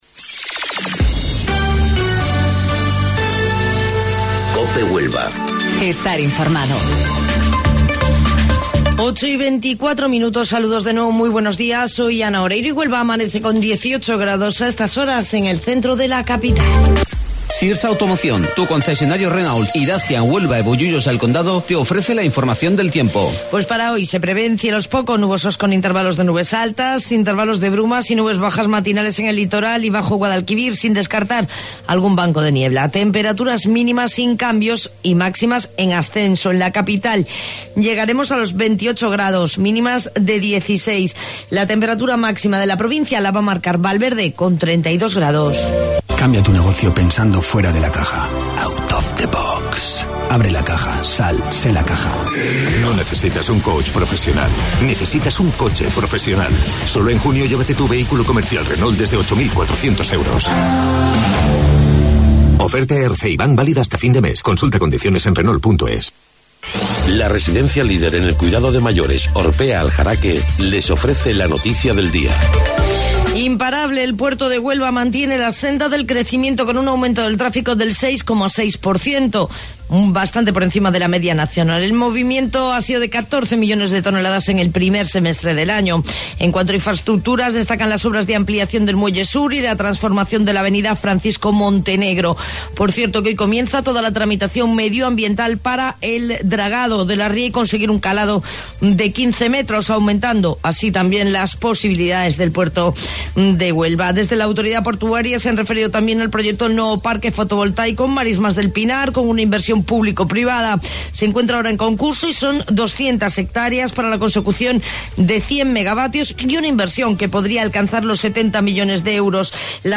AUDIO: Informativo Local 08:25 del 27 de Junio